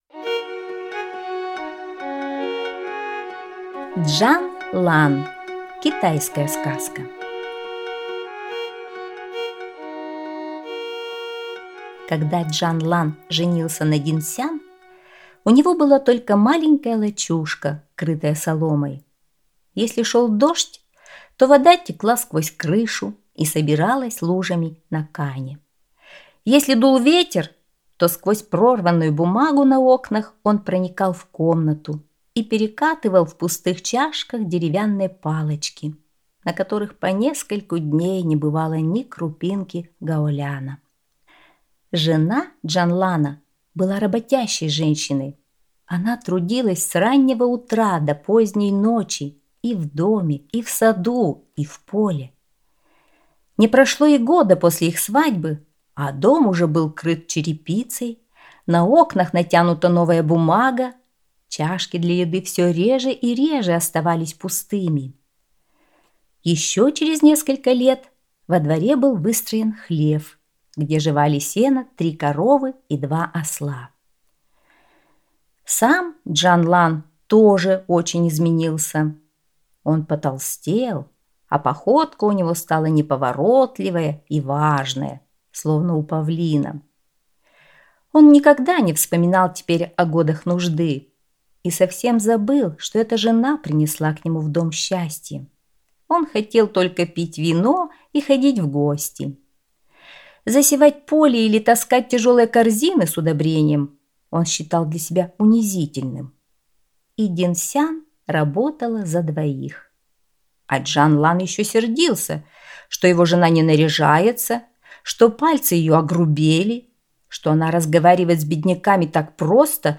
Чжан Лан - китайская аудиосказка - слушать онлайн